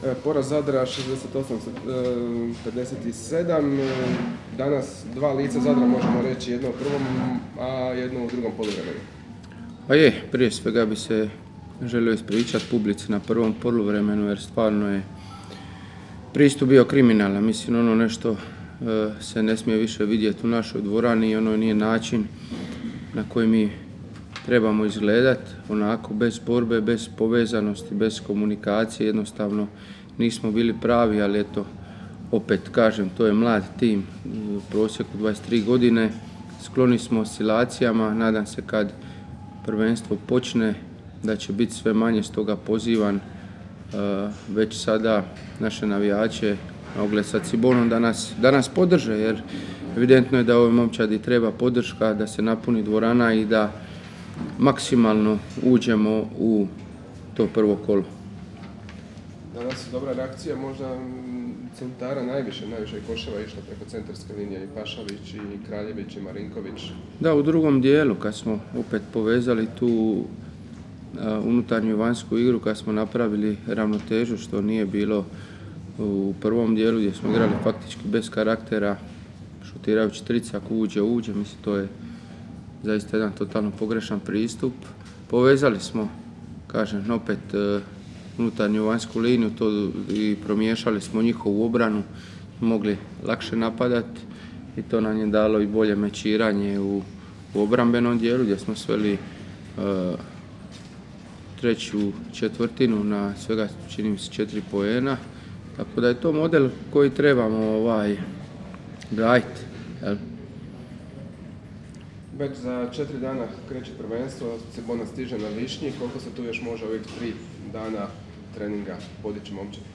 nakon utakmice je izjavio sljedeće: